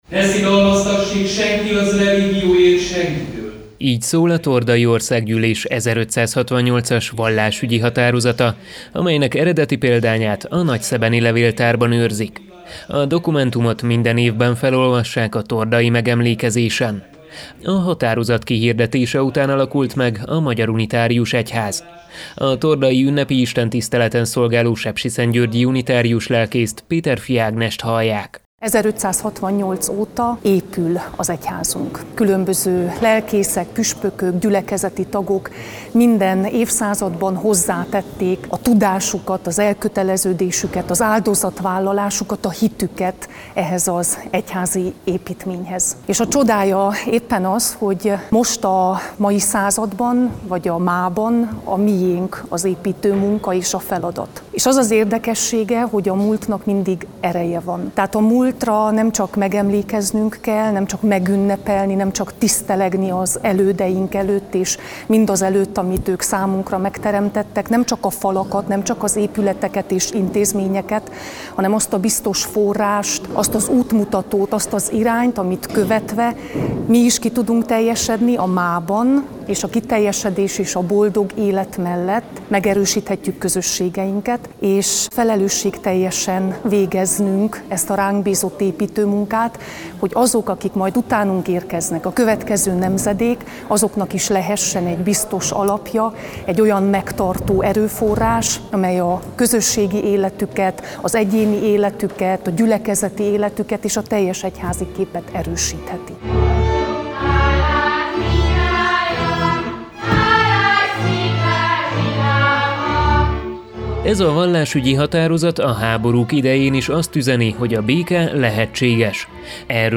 Ünnepi istentisztelet a vallásszabadság napján